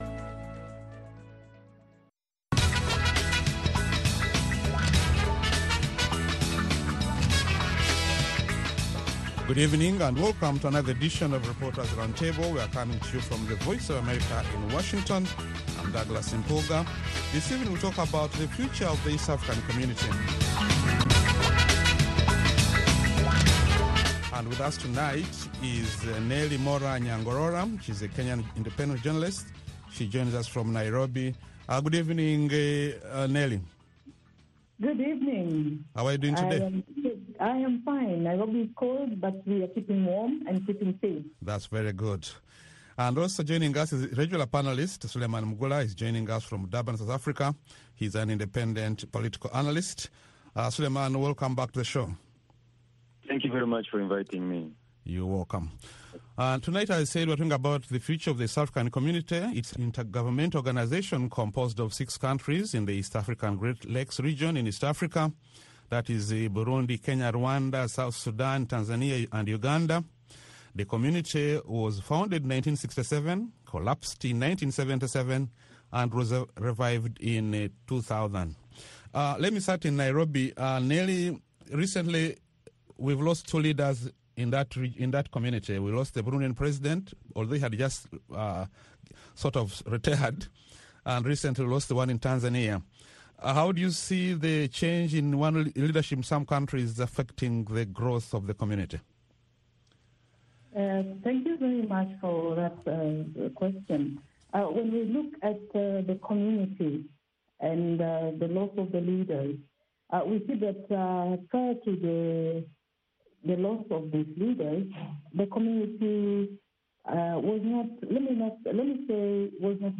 along with a lively panel of journalists, who analyze the week’s major developments in Africa.